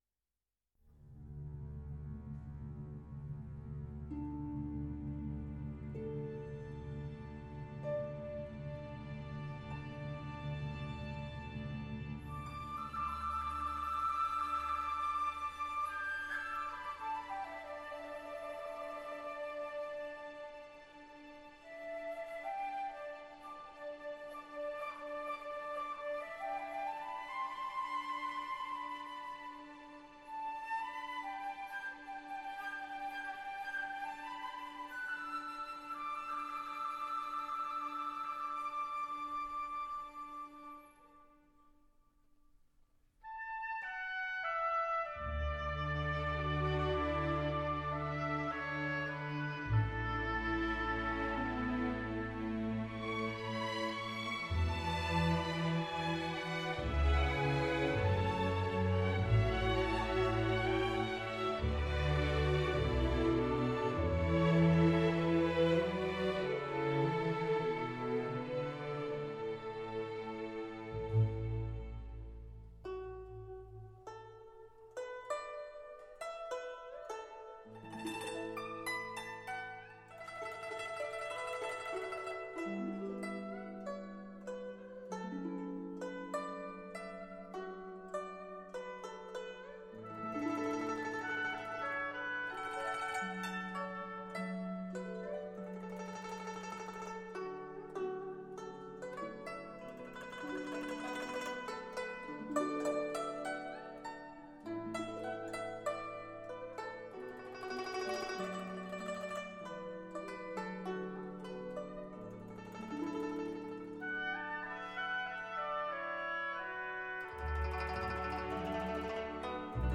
古筝与乐队刚柔并济，水融